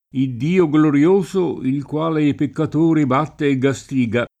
idd&o glorL1So, il kU#le i pekkat1ri b#tte e ggaSt&ga] (Compagni); guarda chi è Colui che gastiga! [